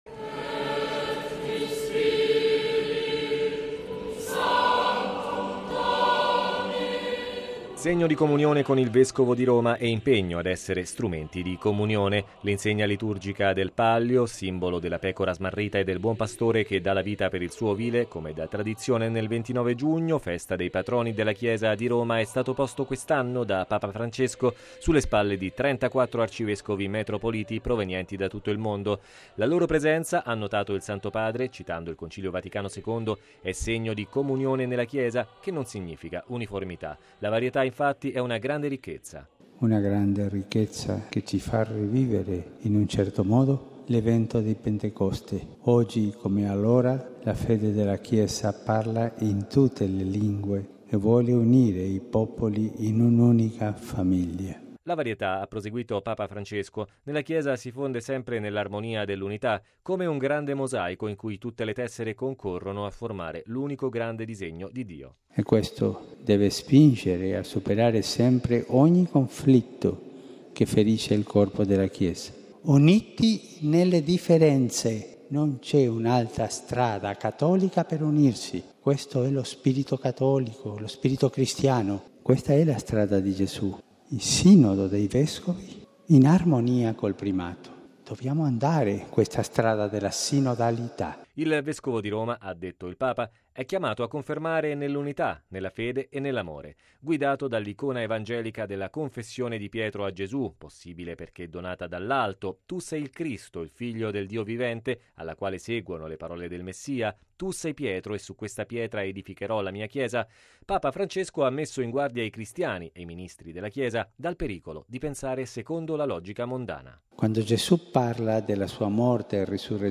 Logo 50Radiogiornale Radio Vaticana